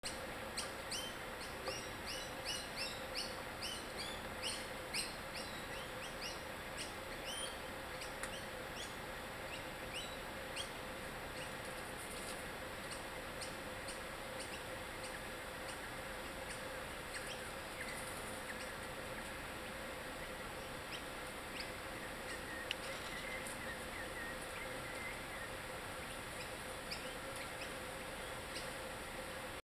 Bush Turkey On Our Patio